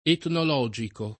etnologico [ etnol 0J iko ]